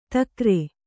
♪ takre